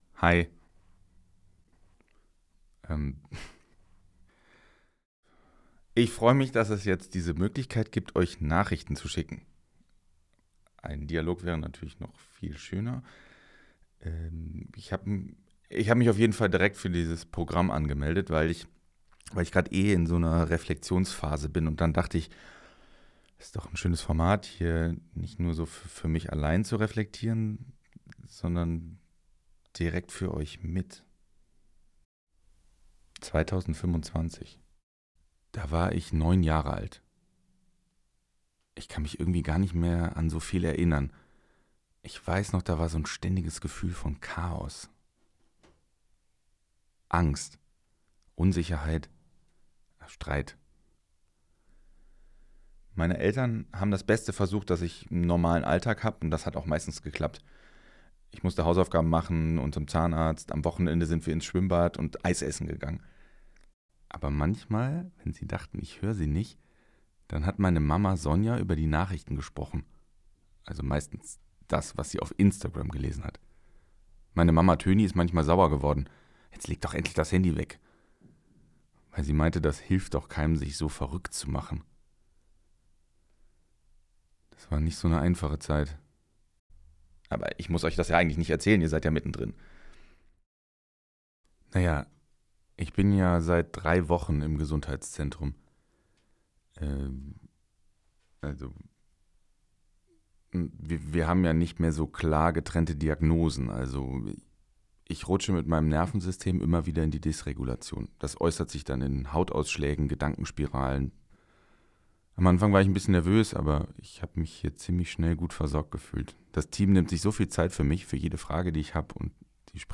Sprachnachrichten zum Thema Gesundheitszentrum, Therapien und transgenerationalen Traumata (11:18 Minuten)